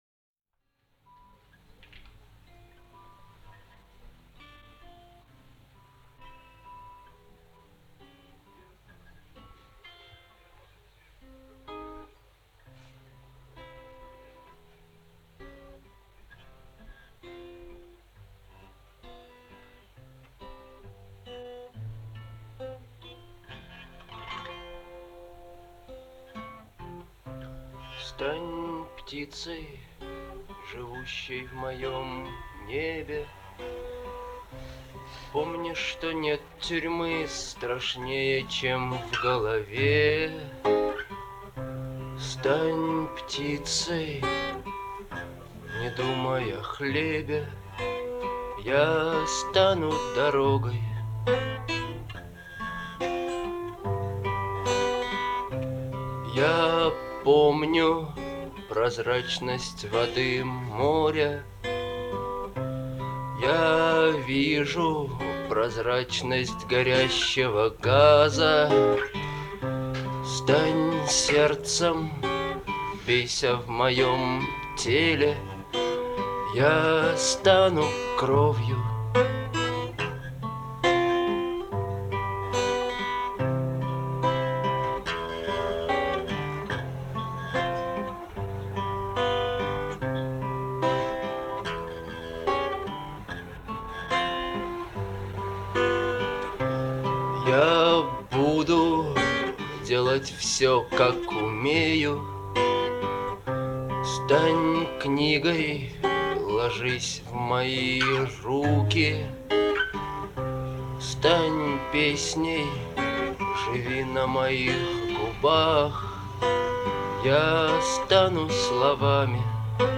относящаяся к жанру рок.
мощные гитарные рифы, запоминающийся мелодичный вокал
атмосферные аранжировки создают уникальную ауру